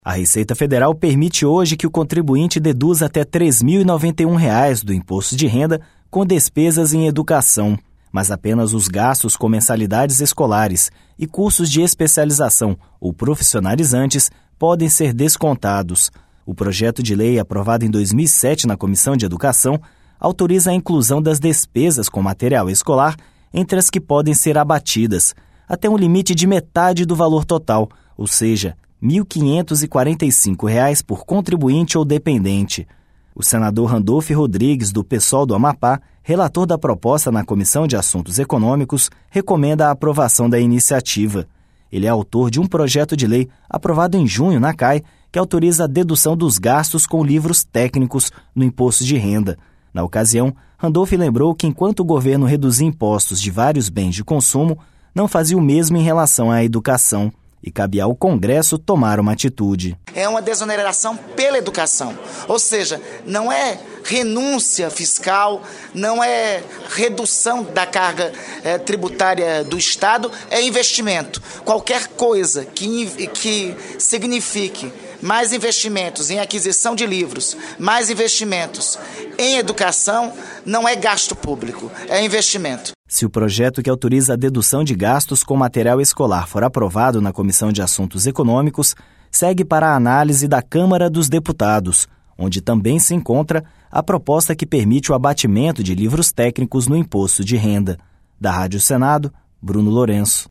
O senador Randolfe Rodrigues, do PSOL do Amapá, relator da proposta na Comissão de Assuntos Econômicos, recomenda a aprovação da iniciativa.